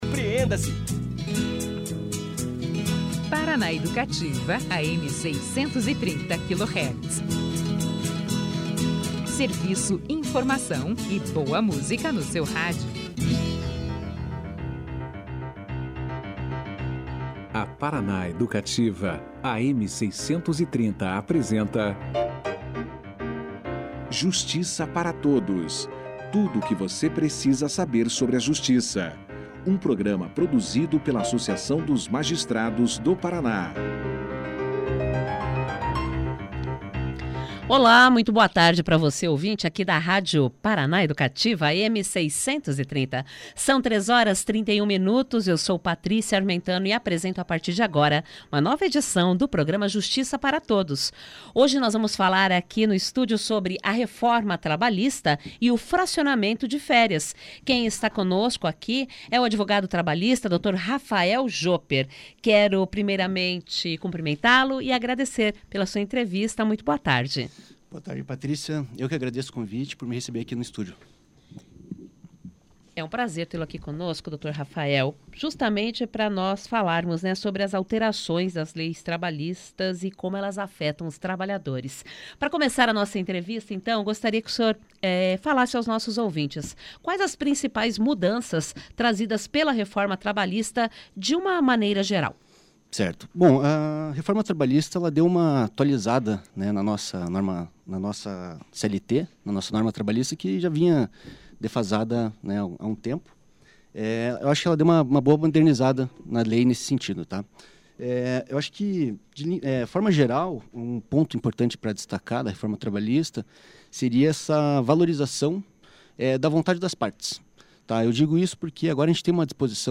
Basta que empregador e empregado entrem em acordo sobre o fracionamento. Confira aqui a entrevista na íntegra.